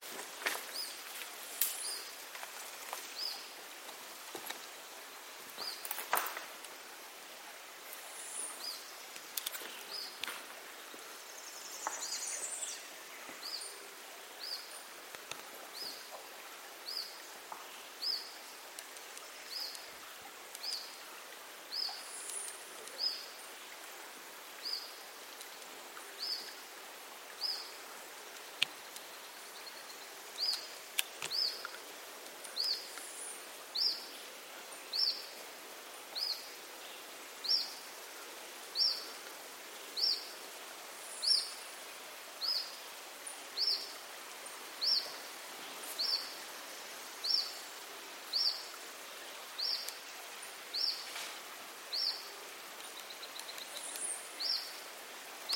Mosqueta Pico Curvo (Acrochordopus burmeisteri)
2 individuos
Nombre en inglés: Rough-legged Tyrannulet
Localidad o área protegida: Valle del Lunarejo
Condición: Silvestre
Certeza: Vocalización Grabada